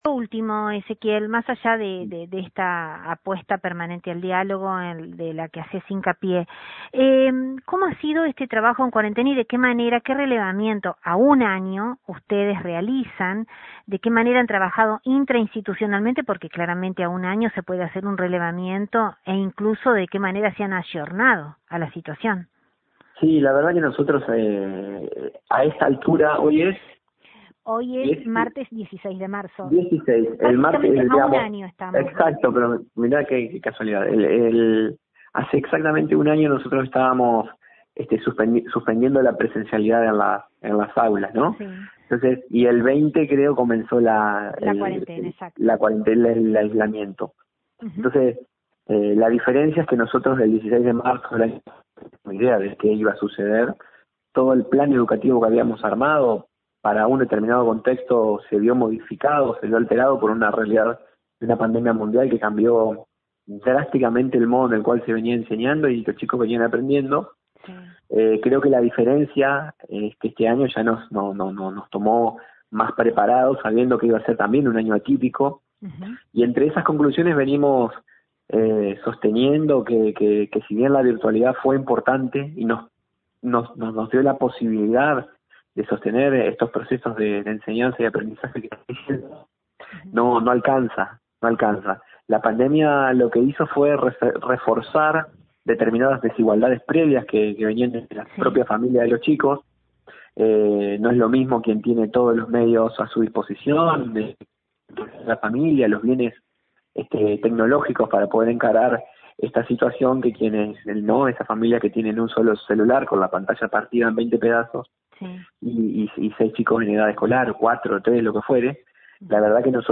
Después de la reunión mantenida entre representantes del Consejo General de Educación y de la Asociación Gremial de Entre Ríos, desde nuestro medio, dialogamos con Ezequiel Coronoffo, Vocal del CGE y partícipe de la misma.